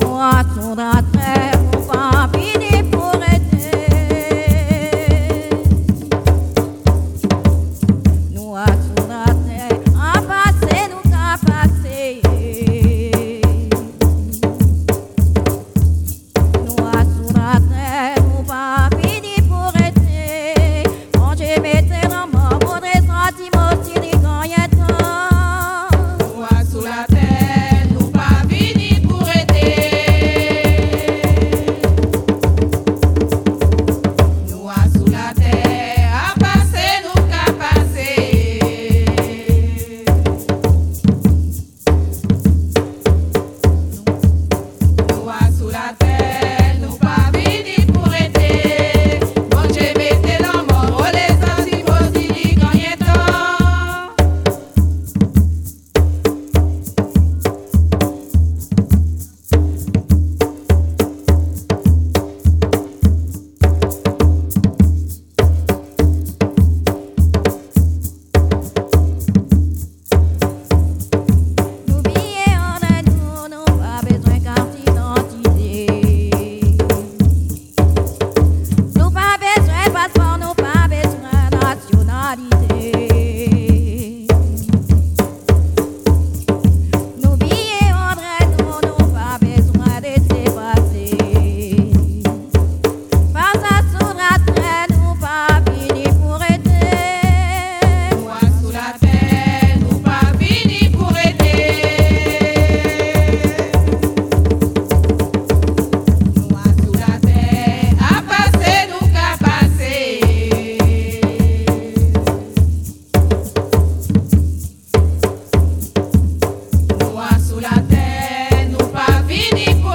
Saint-Georges-de-l'Oyapoc
danse : léròl (créole)
Pièce musicale inédite